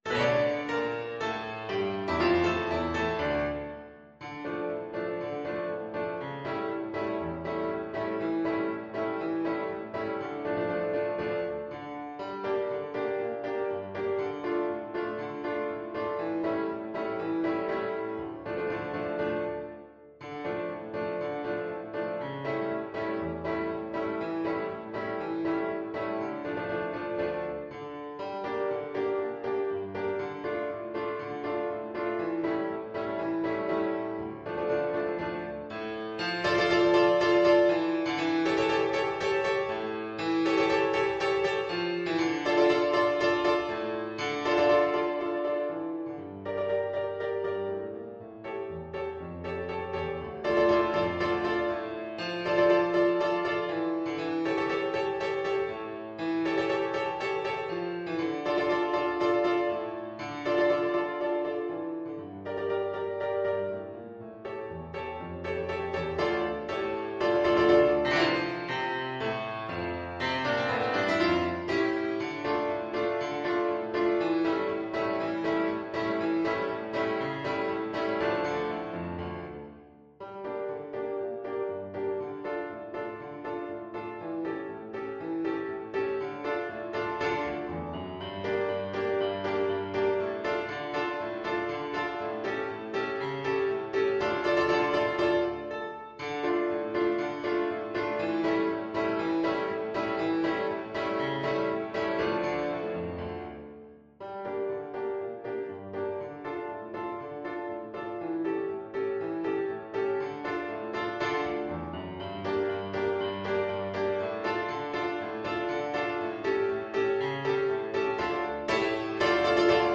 Play (or use space bar on your keyboard) Pause Music Playalong - Piano Accompaniment Playalong Band Accompaniment not yet available transpose reset tempo print settings full screen
D minor (Sounding Pitch) E minor (Clarinet in Bb) (View more D minor Music for Clarinet )
Allegro = c.120 (View more music marked Allegro)
Classical (View more Classical Clarinet Music)